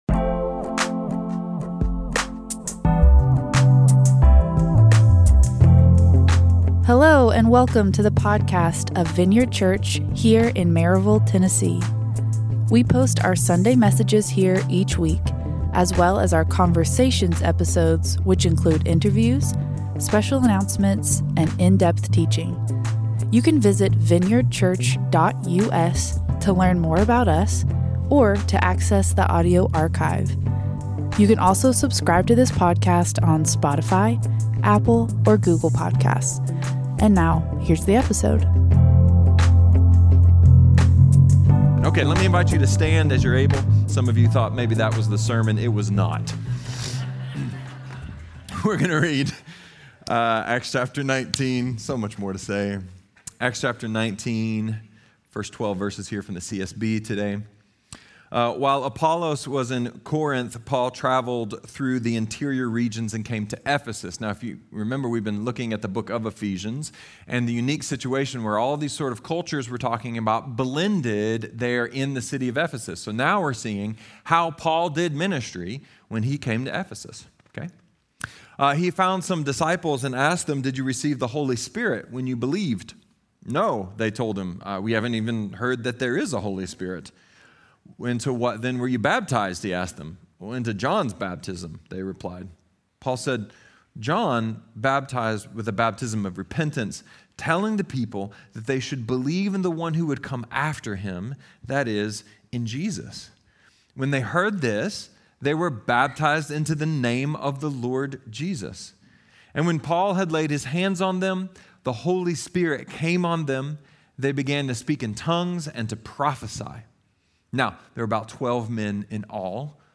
A sermon about how other cultures view the world, how our culture is shifting, and how the timeless gospel applies to all of it!